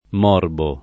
morbo [ m 0 rbo ] s. m.